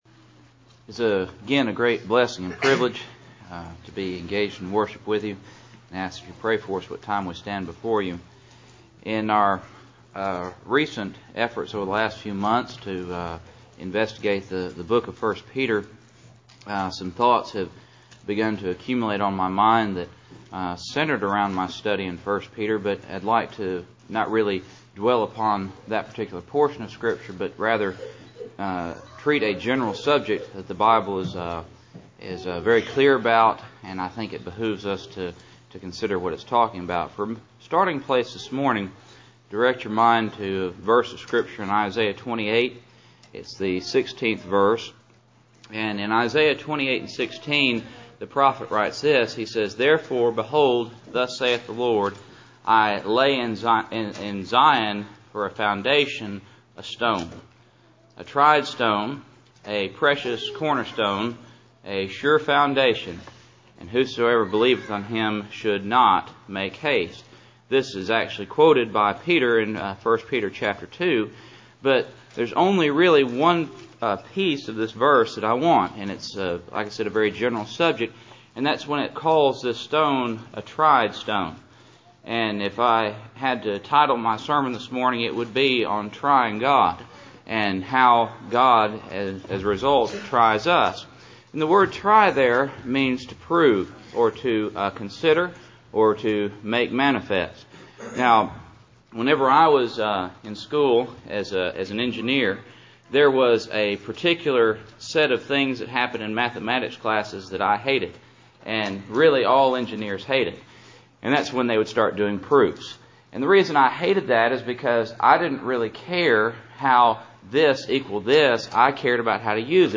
Cool Springs PBC Sunday Morning %todo_render% « How to Act When Tempted I Peter 2:4-6